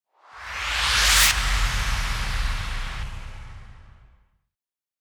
FX-1534-WIPE
FX-1534-WIPE.mp3